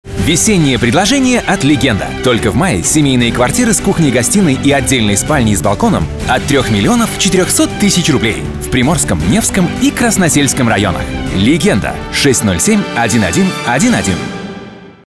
Native russian voiceover artist, actor and narrator.
Sprechprobe: Werbung (Muttersprache):